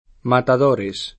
matador [sp. mataD0r] s. m.; pl. matadores [